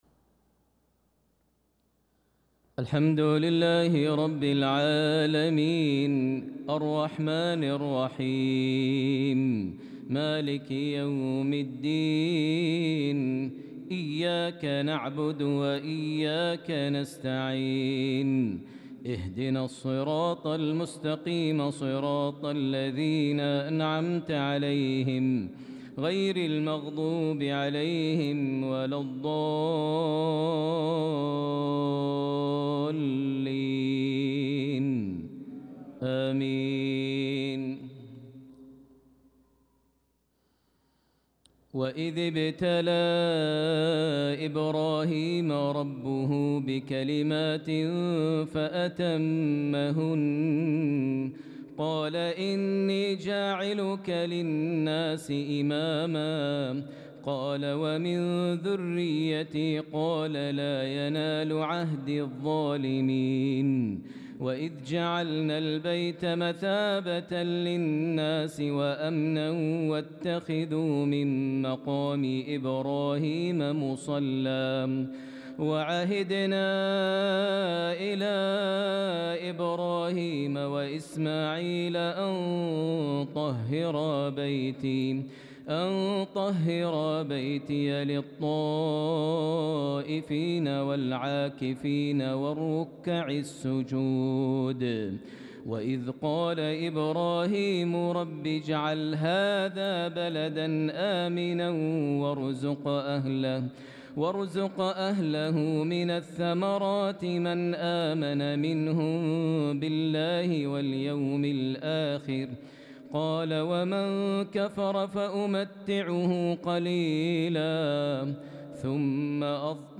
صلاة العشاء للقارئ ماهر المعيقلي 28 ذو الحجة 1445 هـ
تِلَاوَات الْحَرَمَيْن .